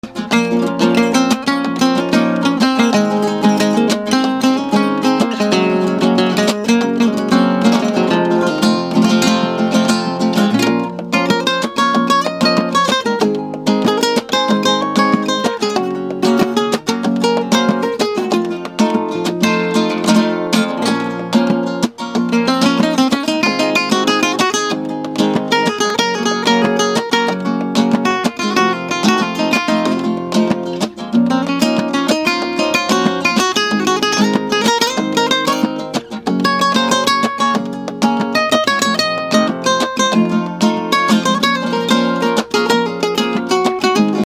How To Play This Flamenco Sound Effects Free Download